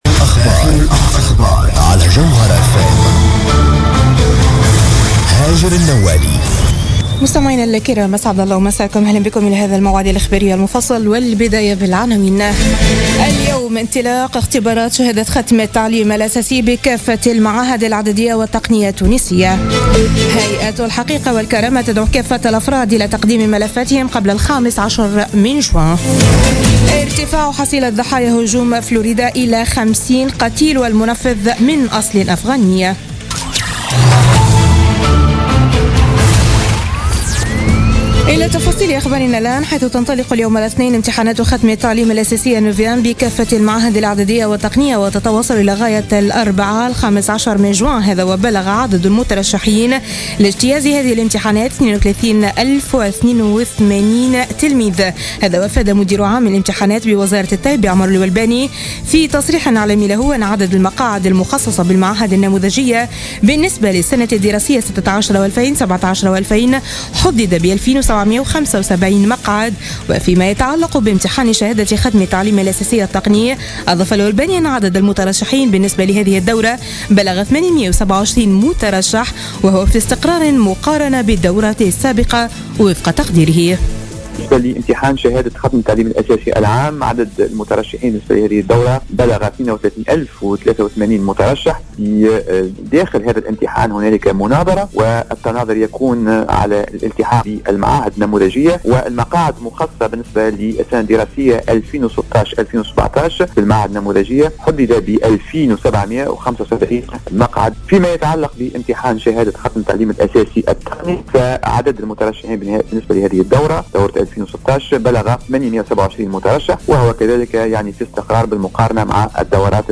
نشرة أخبار منتصف الليل ليوم الإثنين 12 جوان 2016